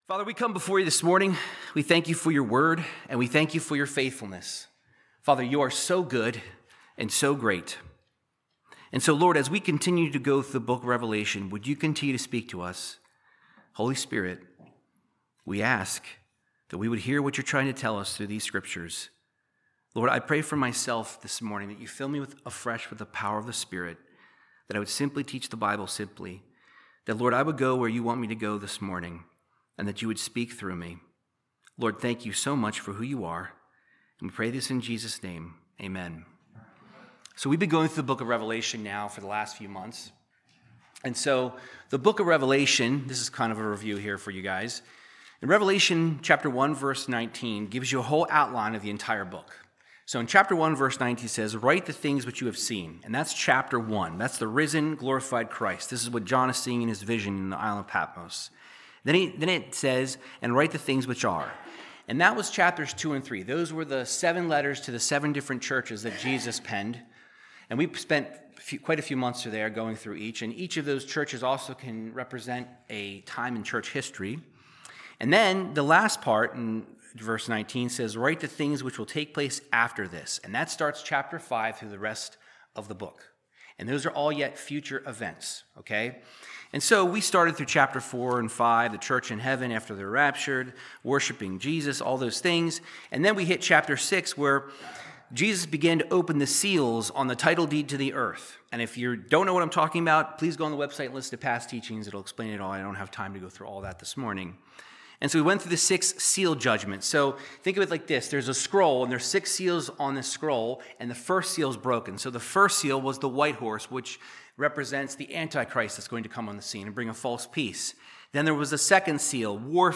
Verse by verse Bible teaching through Revelation 9:1-12